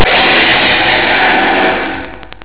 Spews Explosive Balls From Mouth Film Appearances: Godzilla Vs. Megalon (1973) Origin: Megalon is a monster god of the people of Seatopia, a civilization under water. SOUND: Megalon cry Back to monsters section